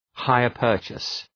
Shkrimi fonetik{,haıər’pɜ:rtʃəs}
hire-purchase.mp3